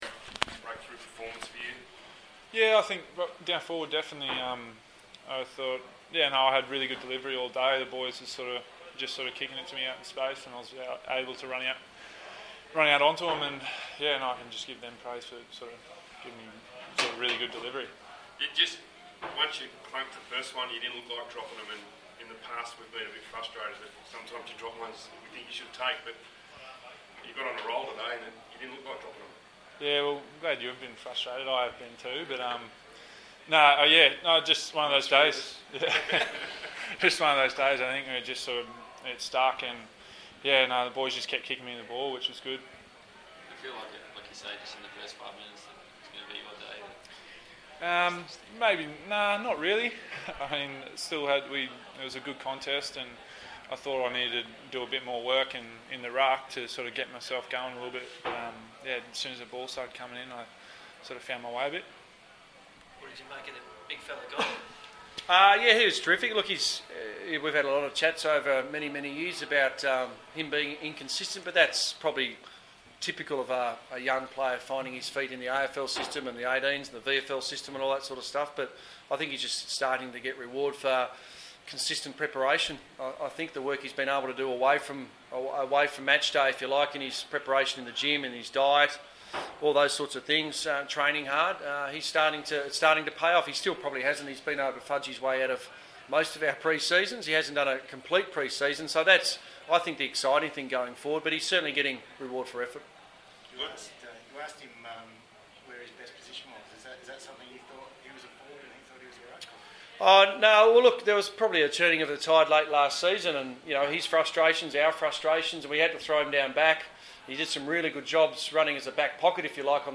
Dixon, McKenna press conference
Audio of Gold Coast forward Charlie Dixon and coach Guy McKenna speaking after the Suns' 44-point win over Greater Western Sydney in Canberra on Saturday.